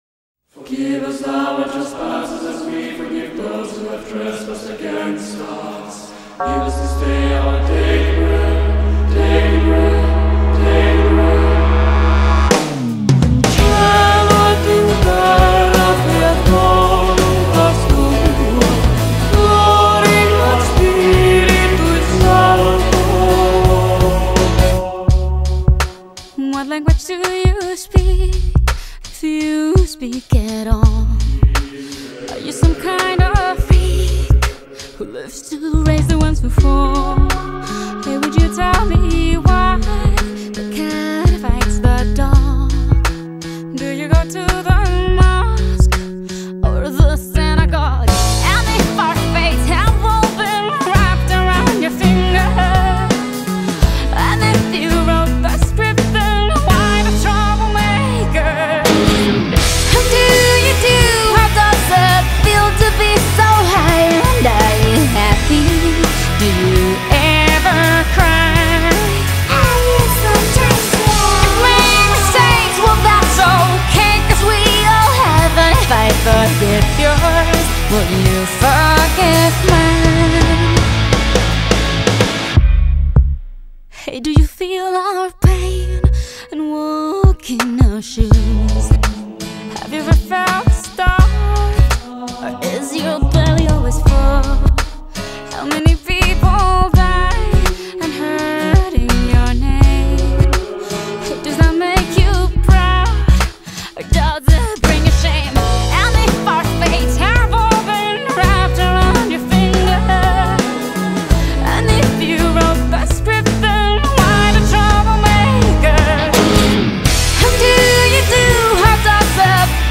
Latin Pop, Pop, Dance-Pop